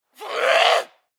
DayZ-Epoch/dayz_sfx/zombie/attack_0.ogg at 76e9a0582e5d4960d17700c1852b2c1dcf864f1b